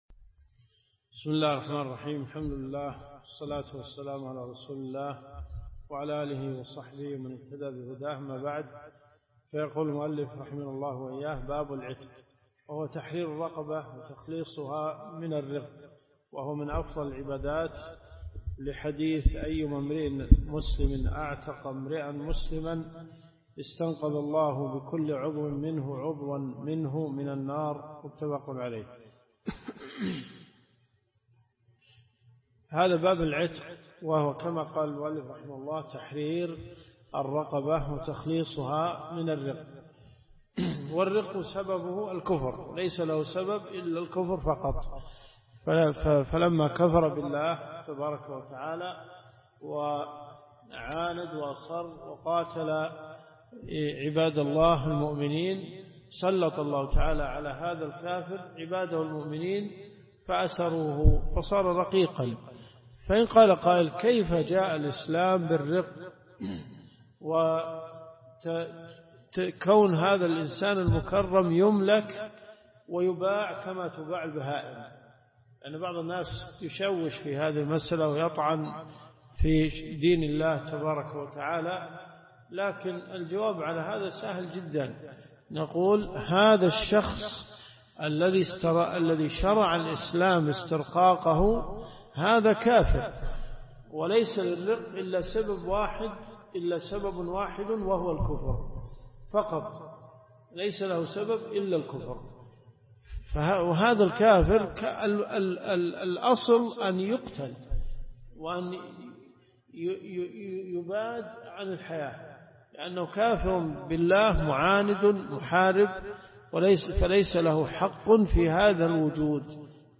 مقطع مأخوذ من شرح آخر ليجبر السقط